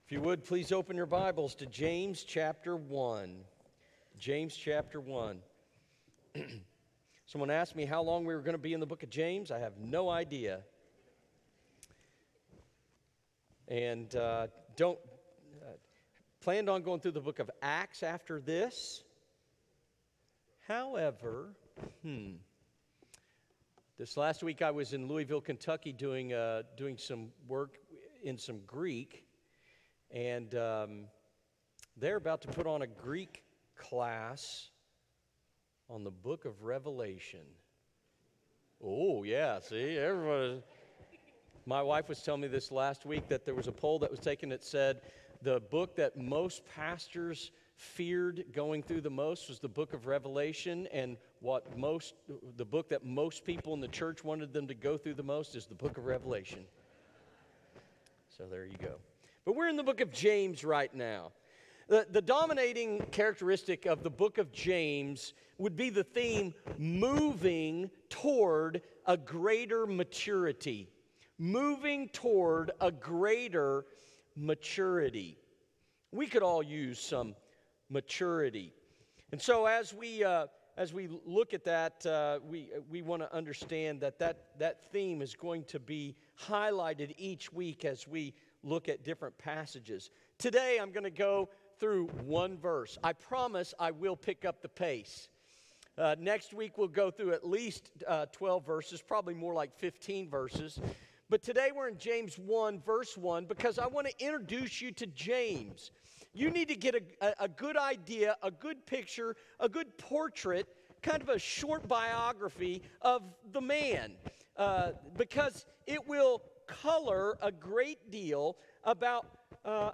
James Sermon Series Introduction: The Tests of Maturity